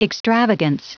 Prononciation du mot extravagance en anglais (fichier audio)
Prononciation du mot : extravagance